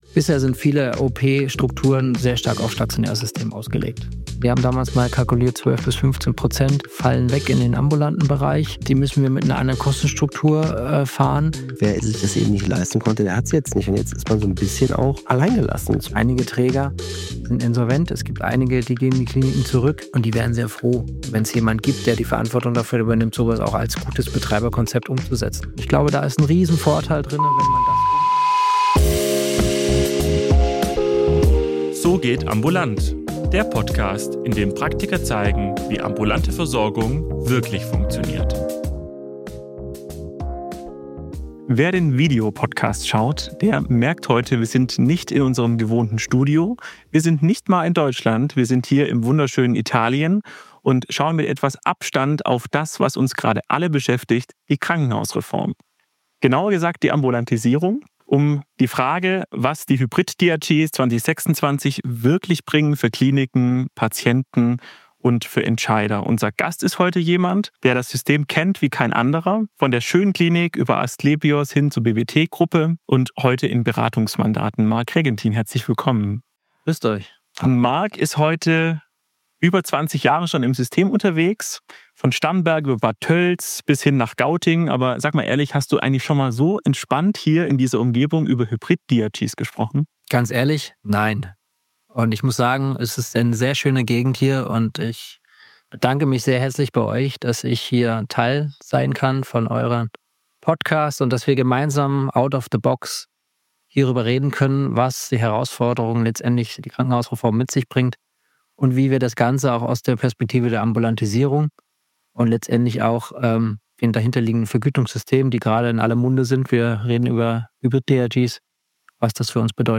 Ein Gespräch in Italien